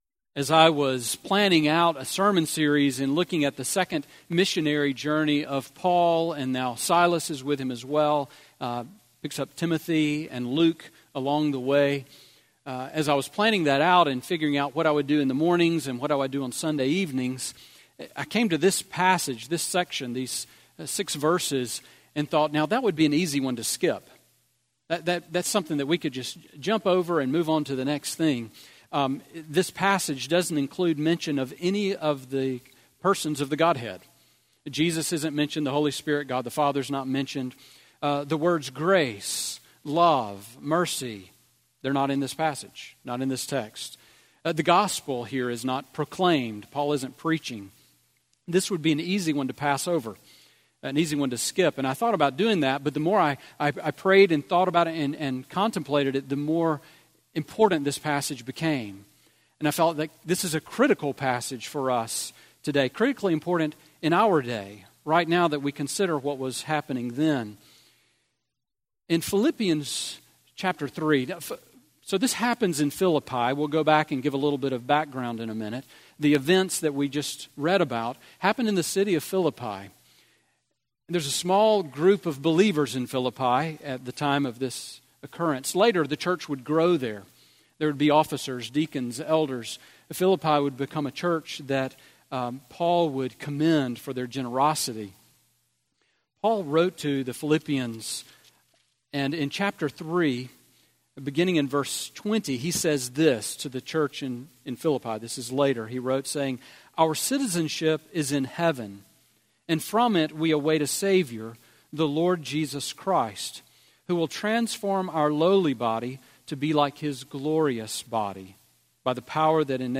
Sermon on Acts 16:35-40 from May 13